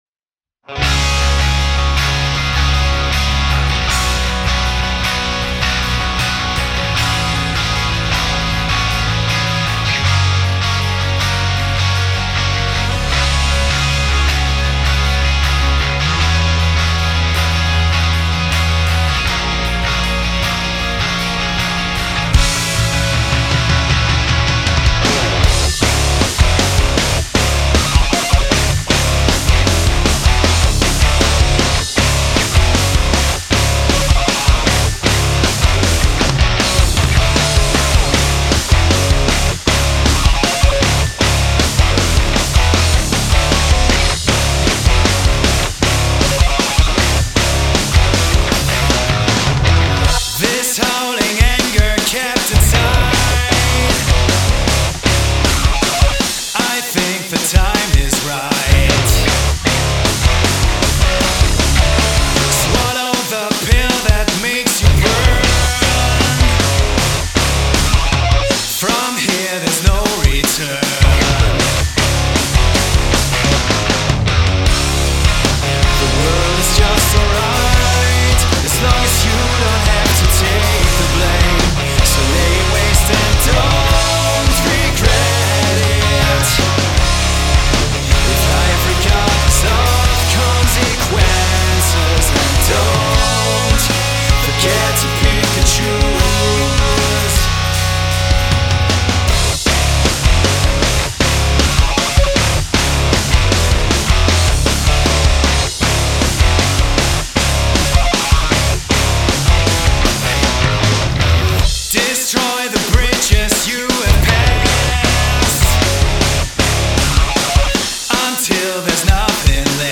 Rock
Ну и компрессор Brighton (как и все АА компрессоры) делает трансиенты синтетическими. На мастере вообще беда.